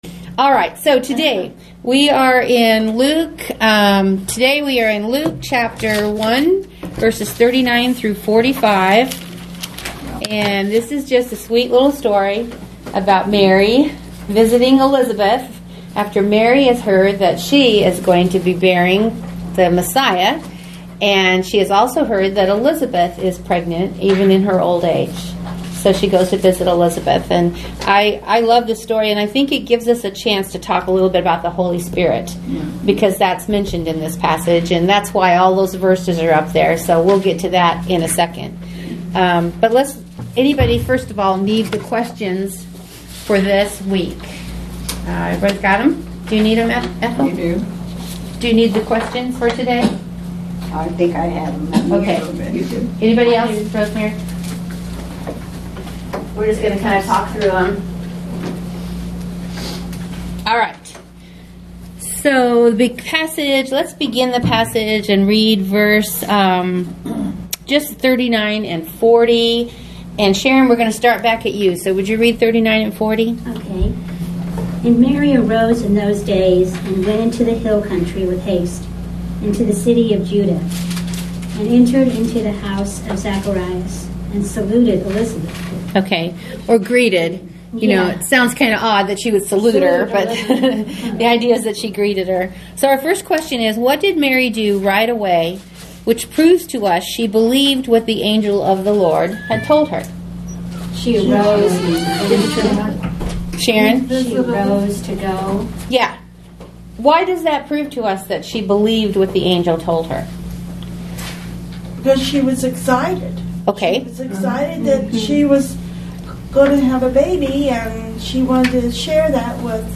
You can click on the link below to listen to audio of our class session.